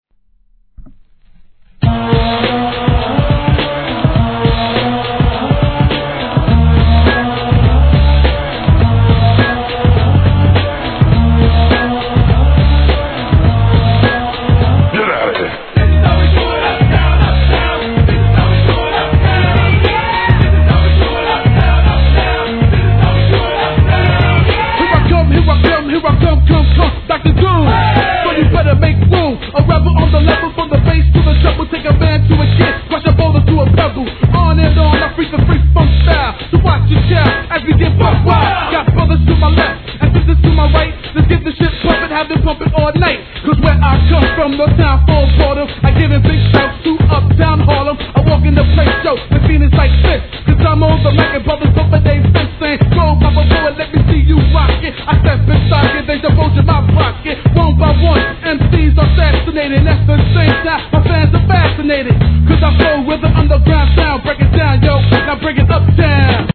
HIP HOP/R&B
分かりやすいネタ使いにキャッチーなフック、そしてこのHIGHテンション!!!